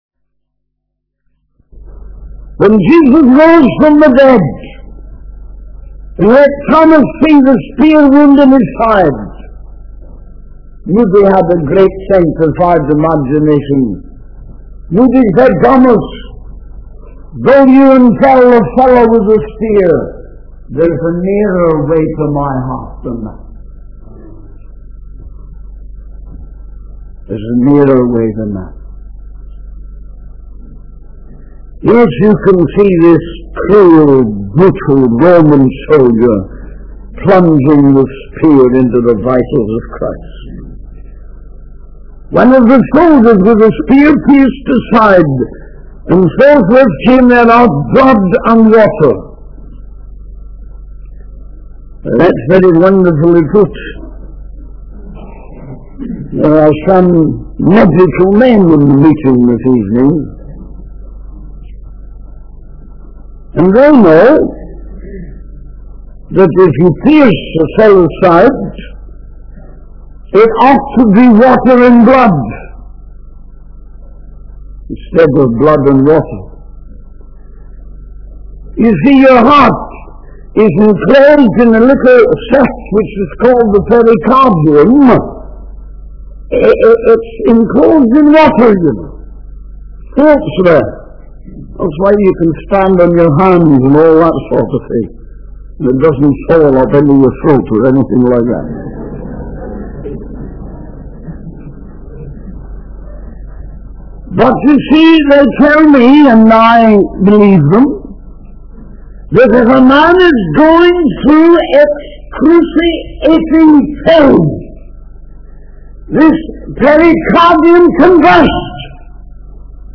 In this sermon, the preacher discusses the purpose and significance of Jesus' resurrection. He emphasizes the importance of understanding the sequence of events, such as the burial of Jesus and the wrapping of his body in linen clothes.